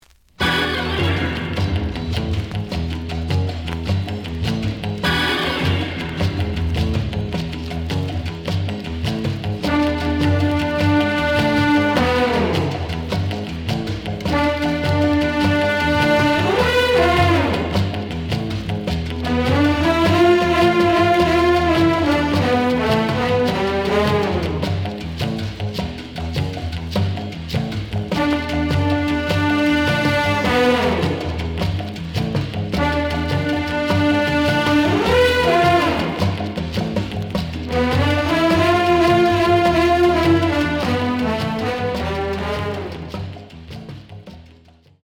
The audio sample is recorded from the actual item.
●Genre: Latin
Some noise on A side.